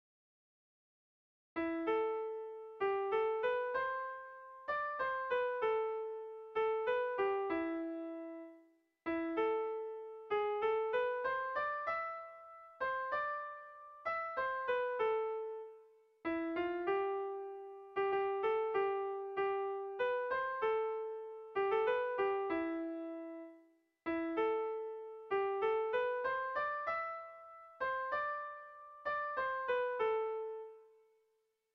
A1A2BA2